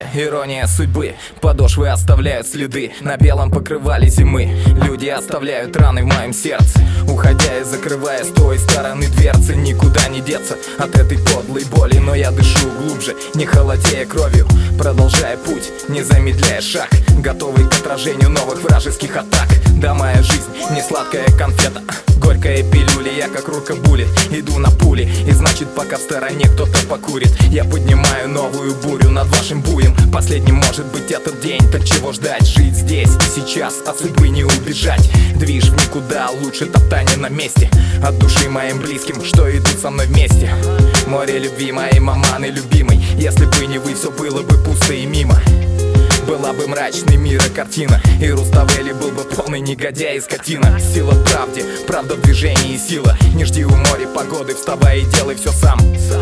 русский рэп
пацанские